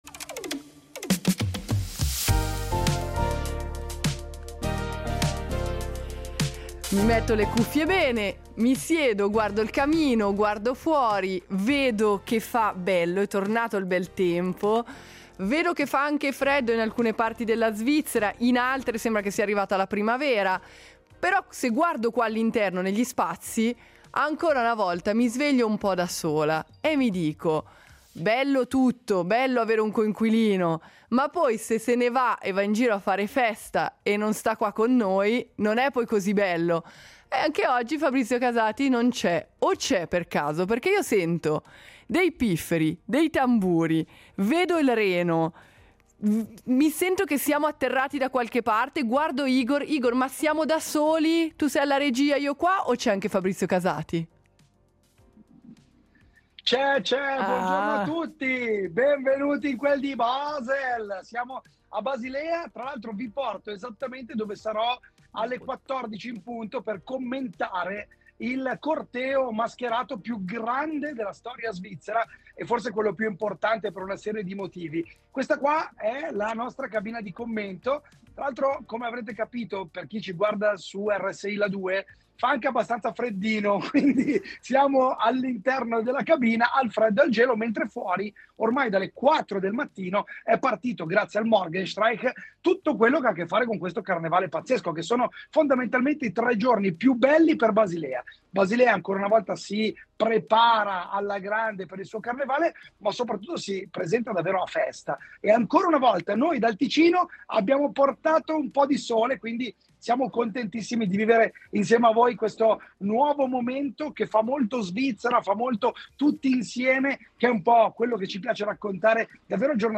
E poi, piano, sono apparse le lanterne illuminate delle Cliquen, dipinte a mano, che hanno iniziato a marciare al suono di pifferi e tamburi.